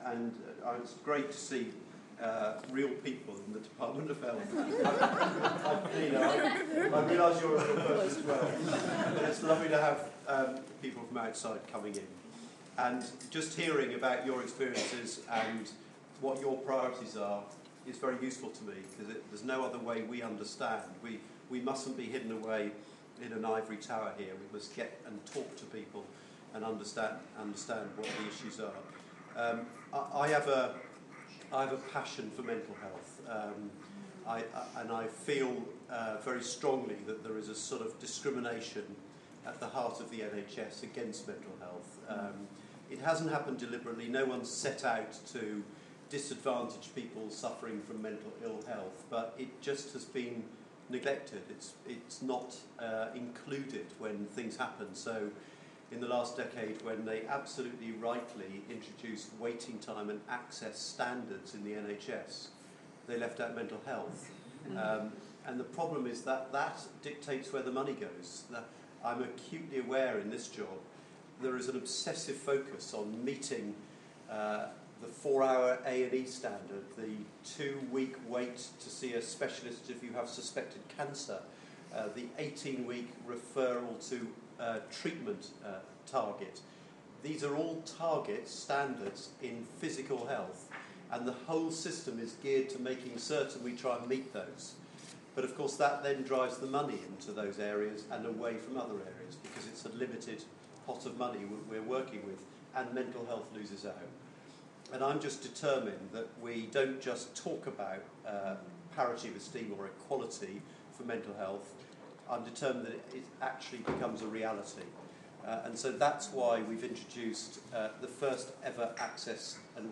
Norman Lamb meets and greets SUCAG at our DH connecting event in Richmond House